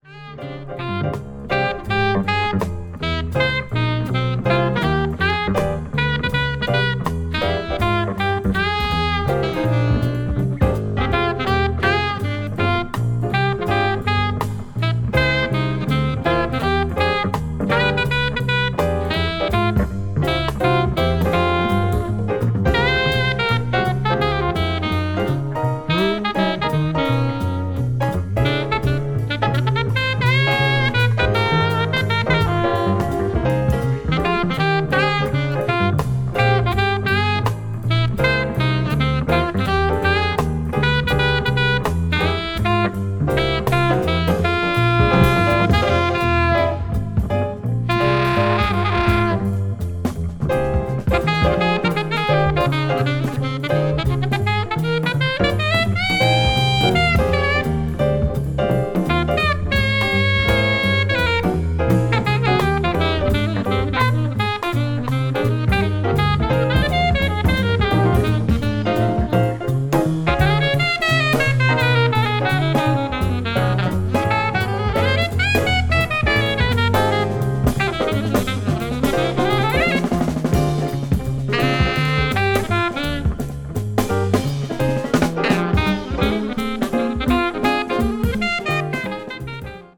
contemporary jazz   modal jazz   post bop   spritual jazz